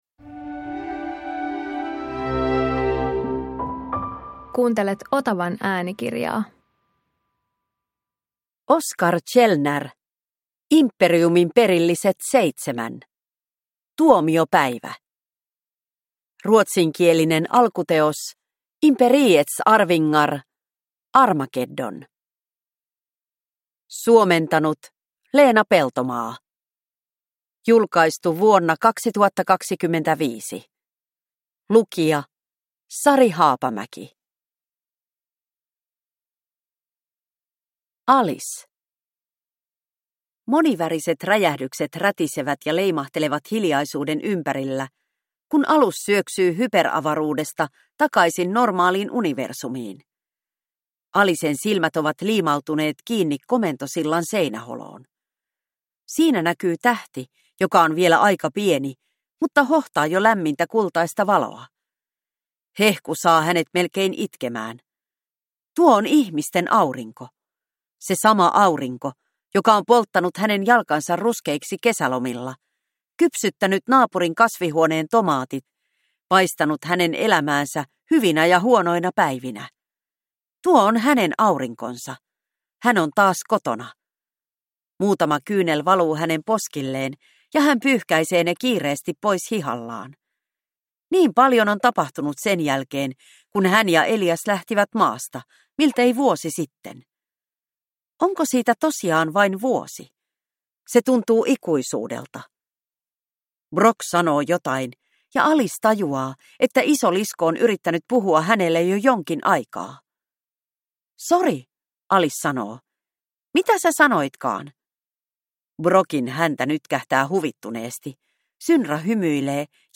Imperiumin perilliset 7 Tuomiopäivä – Ljudbok